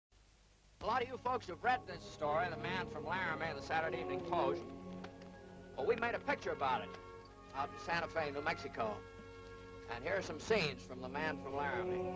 《라라미에서 온 사나이》 예고편(1955)에서 스튜어트의 알아볼 수 있는 억양